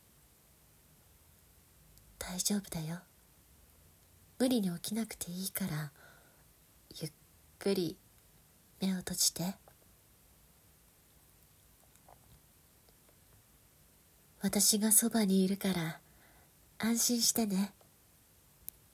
優しい彼女が、眠る前のあなたに穏やかな声で本を読み聞かせるシチュエーションボイスです。
心地よい声と優しい囁きで、あなたの心を癒やし、眠りに誘います。